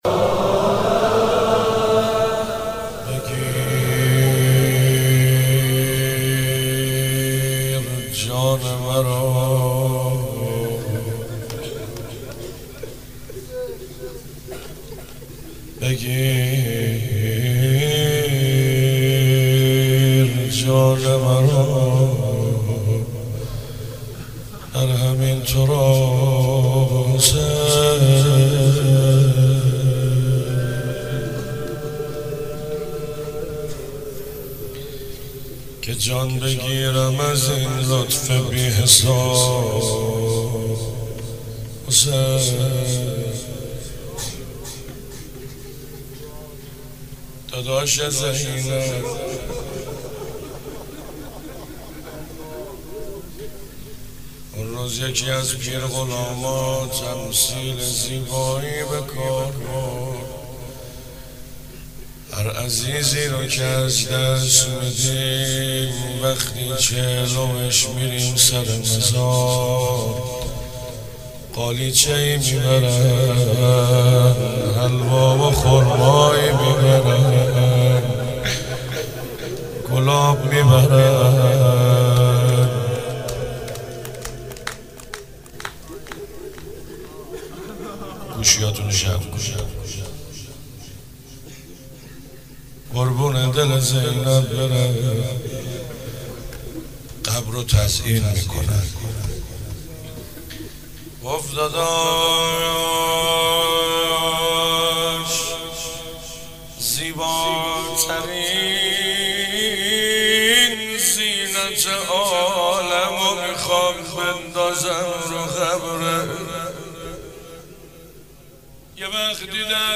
02 heiat alamdar mashhad.mp3